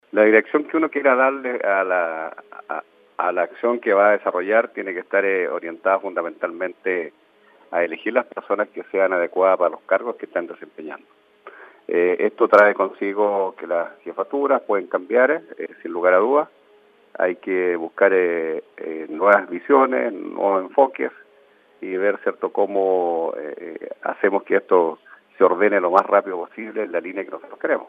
alcalde-electo-osorno.mp3